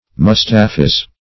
Search Result for " mustahfiz" : The Collaborative International Dictionary of English v.0.48: Mustahfiz \Mus`tah"fiz`\, n. [Turk.